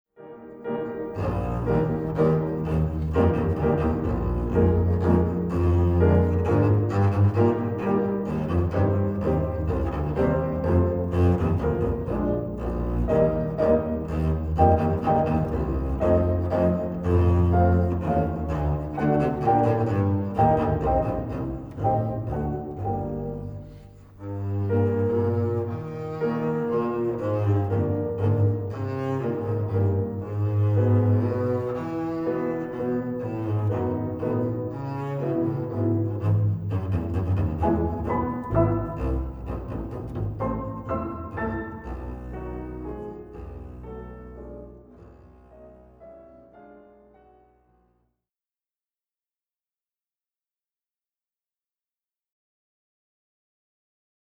• Der Kontrabass (auch Bassgeige genannt) ist das grösste und tiefste Instrument der Streicher-Familie.
Tonbeispiel Kontrabass klassisch:
04-Kontrabass-Klassisch.mp3